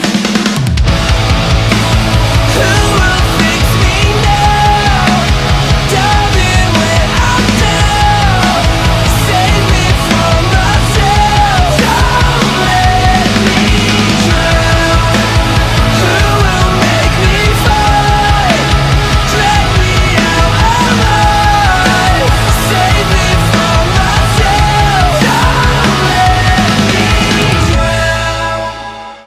• Качество: 192, Stereo
мужской вокал
громкие
Alternative Metal
pop-punk
сильный голос